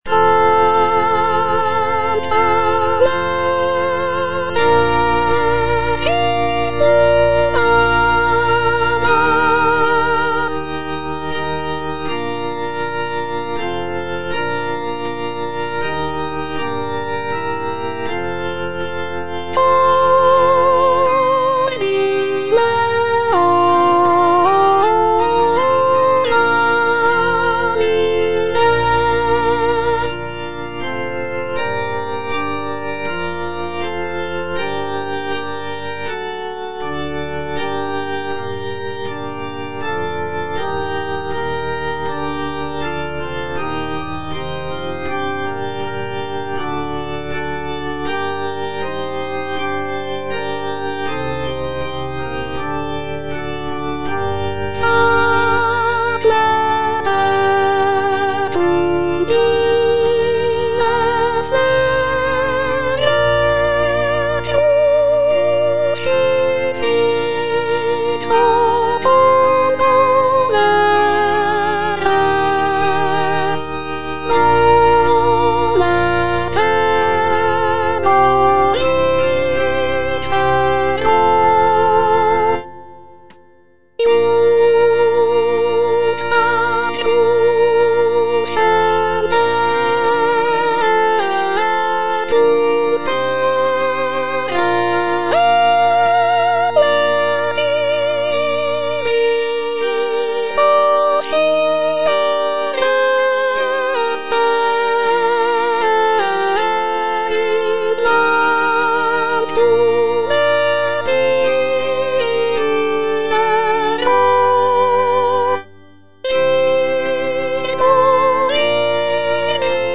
G.P. DA PALESTRINA - STABAT MATER Sancta Mater, istud agas (soprano II) (Voice with metronome) Ads stop: auto-stop
sacred choral work